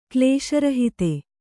♪ klēśa rahite